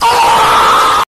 death_sound.wav